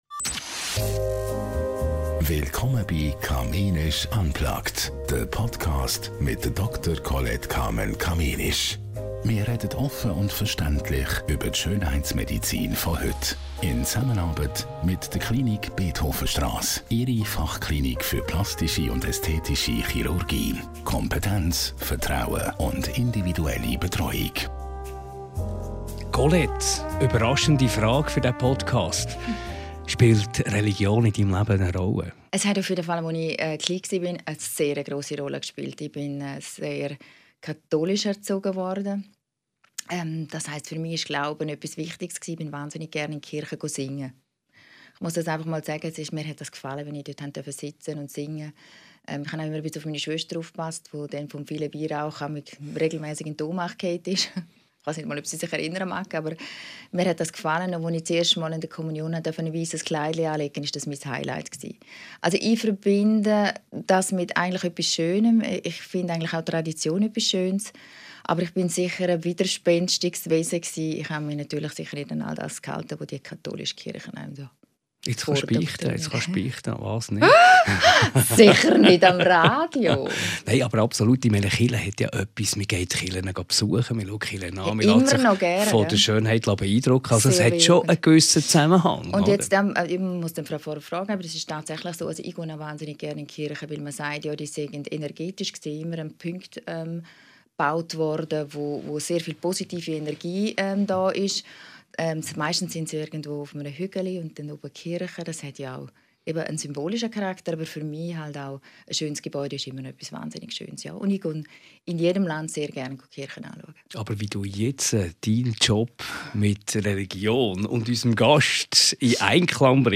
Ein Gespräch über Eitelkeit, Selbstfürsorge und die Frage, ob äussere Schönheit und Glaube wirklich ein Widerspruch sind.